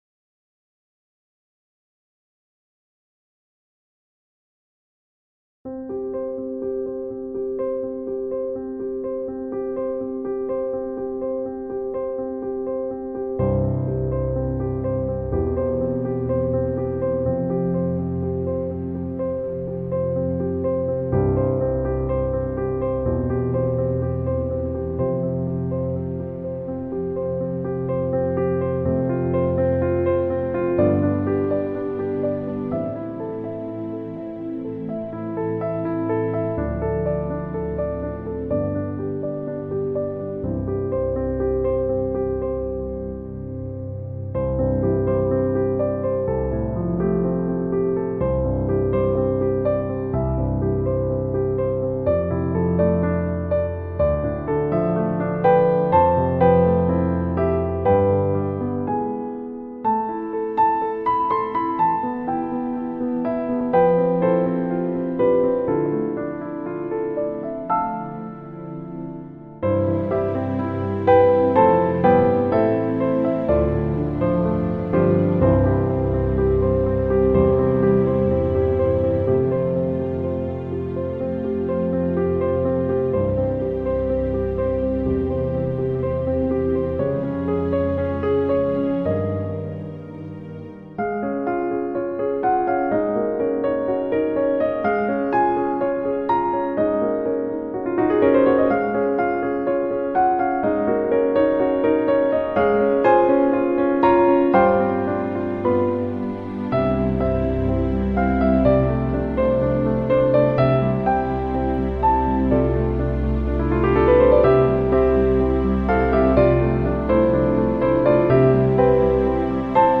A service for 31st January 2021